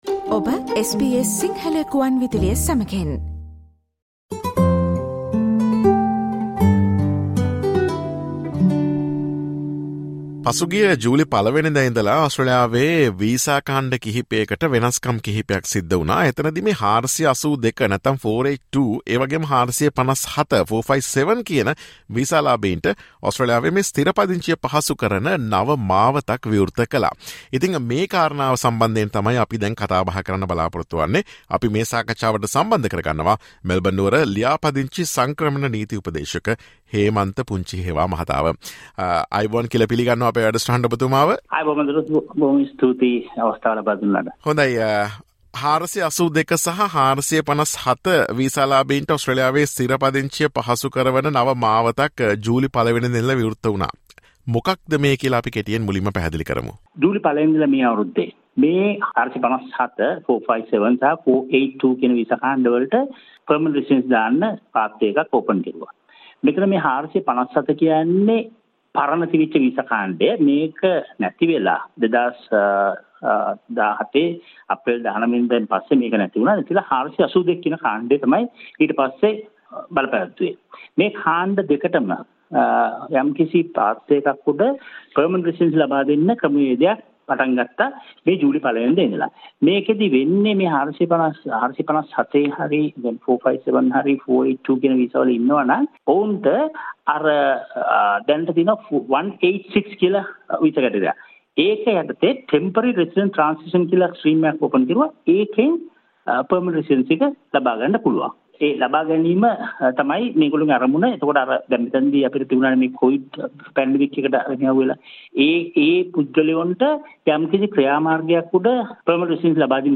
2022 ජූලි 01 සිට 482 සහ 457 වීසා ලාභීන්ට ඔස්ට්‍රේලියාවේ ස්ථිර පදිංචිය පහසු කරවන නව මාවත පිළිබඳ SBS සිංහල ගුවන් විදුලිය ගෙන එන සාකච්ඡාවට සවන් දෙන්න.